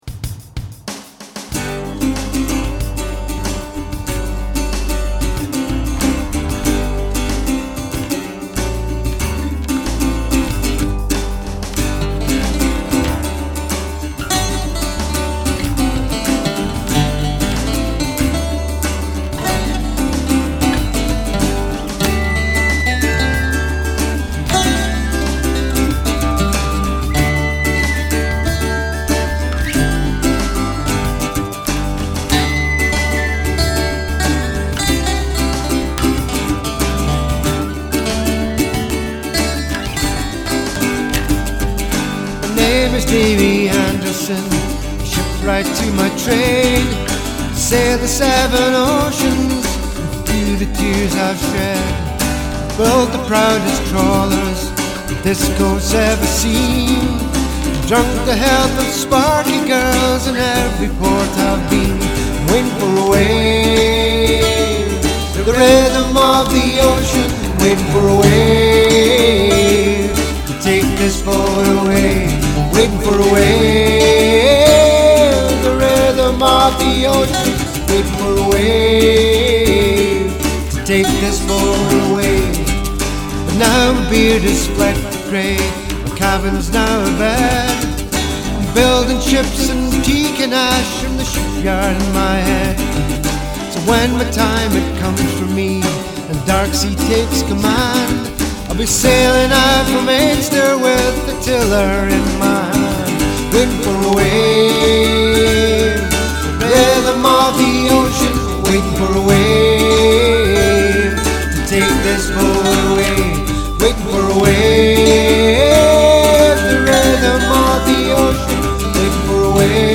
contemporary Scottish folk songs